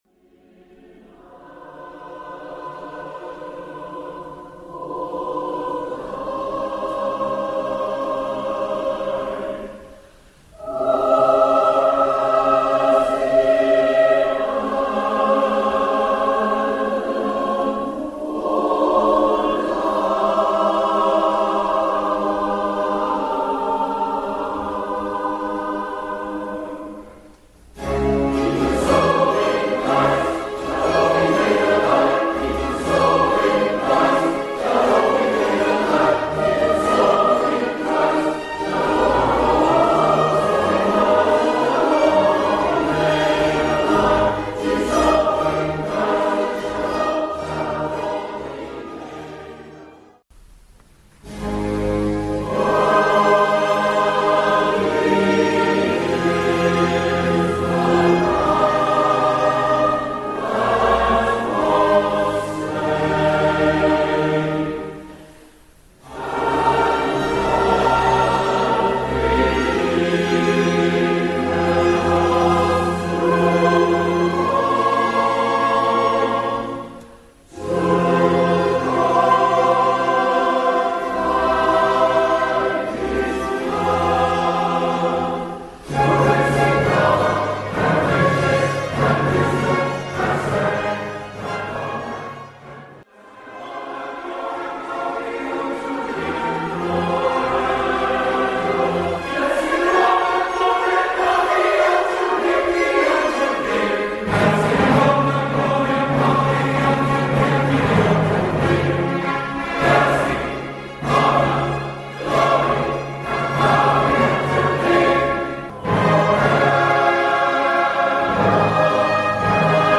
We are a well established choir with around 60 singers and three dozen Friends, based in Dorking, Surrey, and we perform 3 or 4 concerts annually with a varied repertoire from the 16th century to contemporary music.